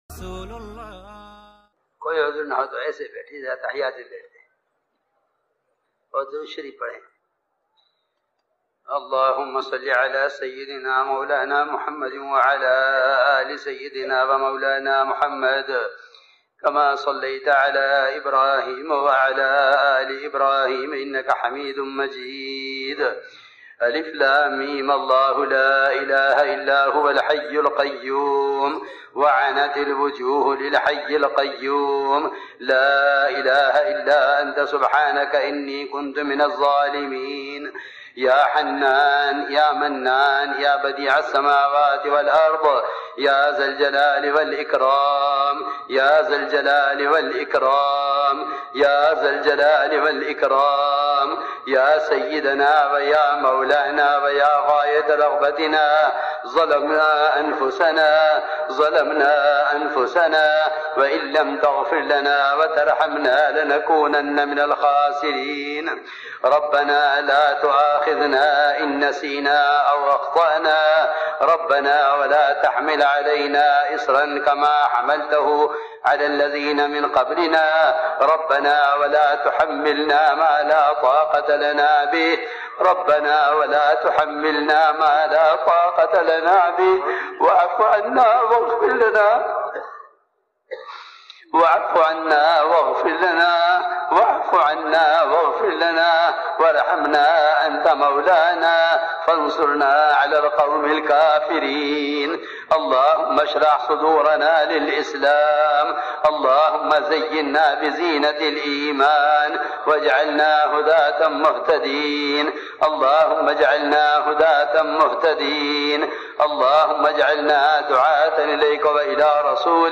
Organised by Markaz Nizamuddin, the three-day Ijtema in Aurangabad, in Maharashtra INDIA starts Saturday after Fajr (Indian time) inshaAllah